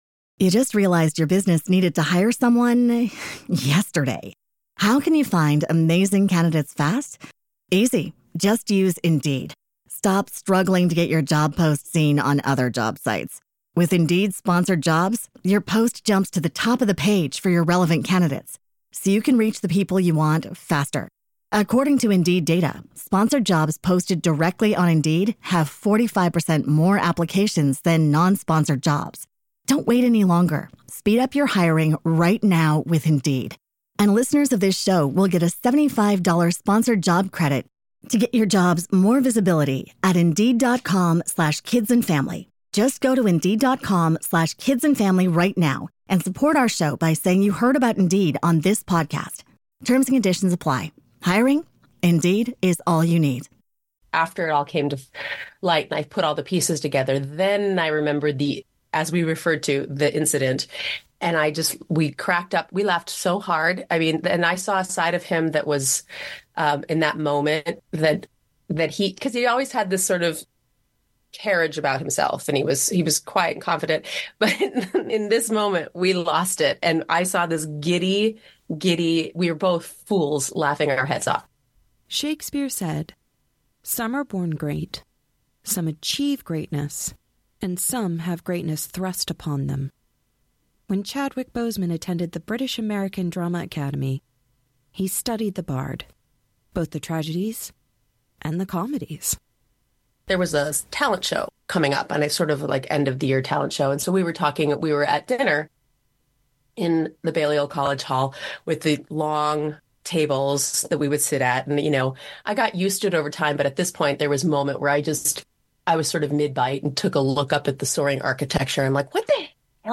speaks with a professor and fellow classmate of Chadwick Boseman when he attended Oxford University for a summer acting course. The origin story of an artist who had never considered acting before - only to become on of the greatest cinematic heroes of all time.